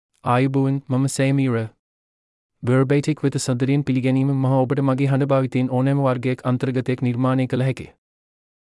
MaleSinhala (Sri Lanka)
Sameera is a male AI voice for Sinhala (Sri Lanka).
Voice sample
Male
Sameera delivers clear pronunciation with authentic Sri Lanka Sinhala intonation, making your content sound professionally produced.